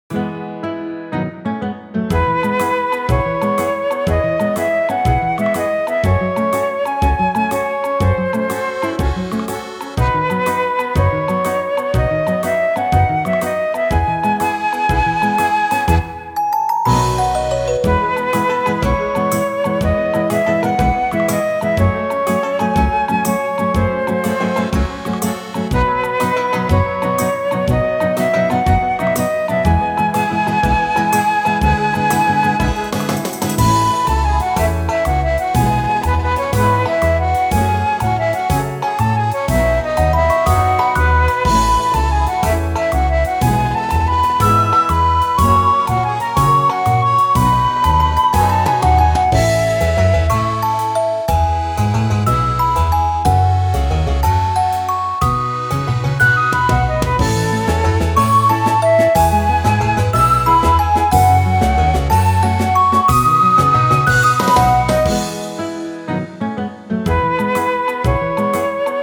ogg(L) 村 おだやか 明るい フルート
牧歌的で温かみのある曲調に乗せてフルートがまったりと歌う。